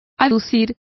Complete with pronunciation of the translation of alleged.